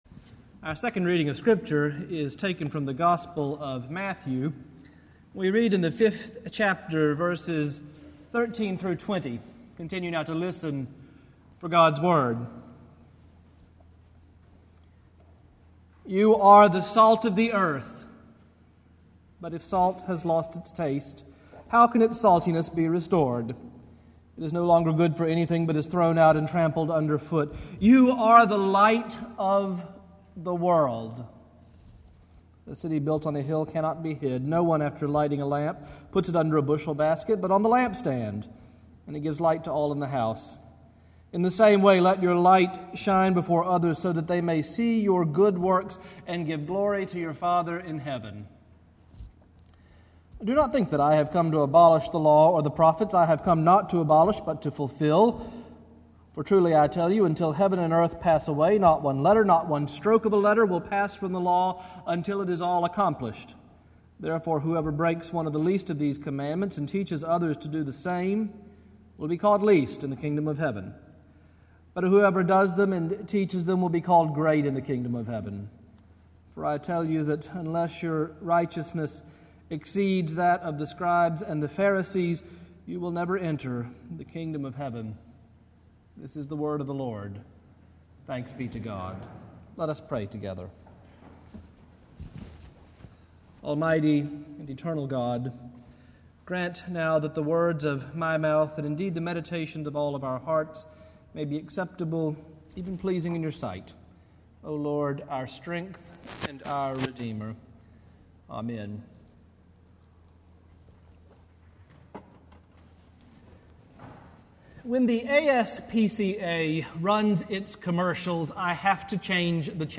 Morningside Presbyterian Church - Atlanta, GA: Sermons: Getting the Right pH Balance